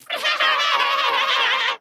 Звуки чужого
Звук инопланетного смеха